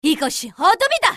slayer_f_voc_skill_fullbirst.mp3